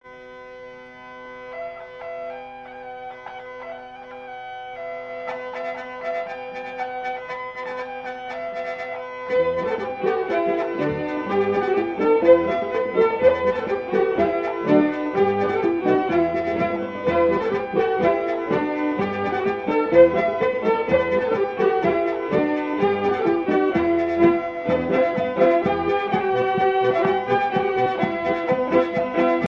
Marais Breton Vendéen
danse : polka